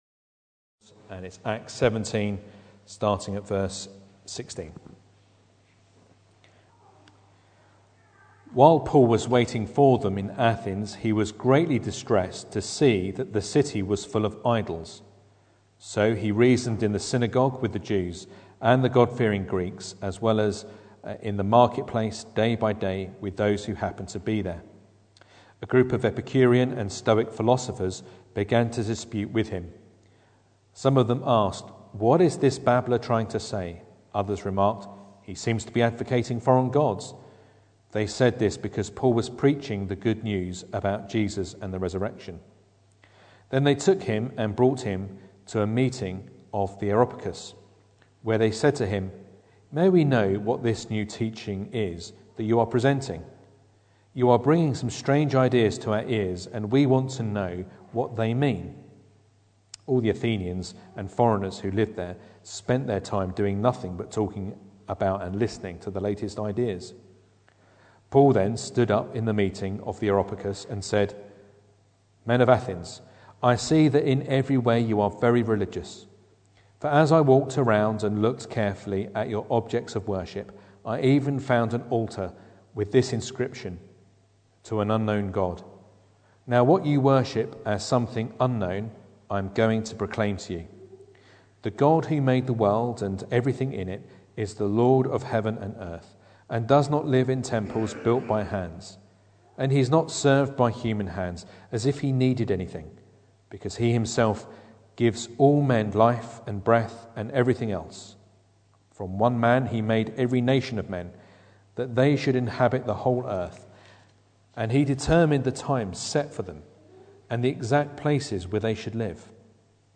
Acts 17:16-33 Service Type: Sunday Evening Bible Text